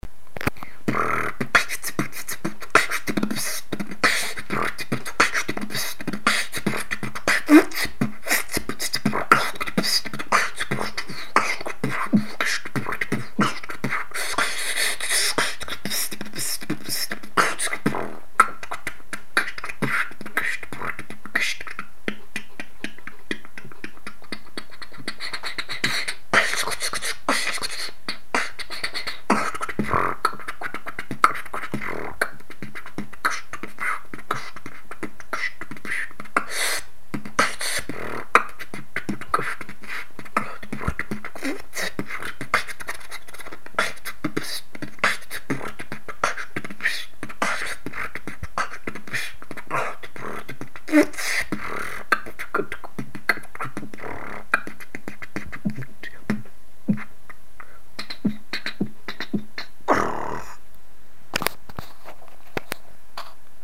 Голосуем фристайл сделали от балды так что не ругайте)
ритм оч понравился.